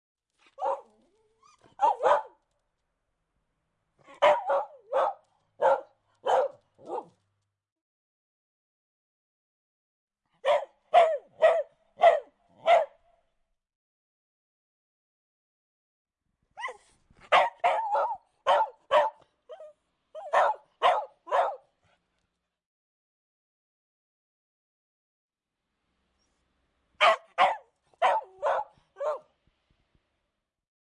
山上的狗叫声
描述：咆哮在匈牙利的北部山地森林里的一条大牧羊犬。周围的群山呼应着声音。
标签： 狂吠 回声
声道立体声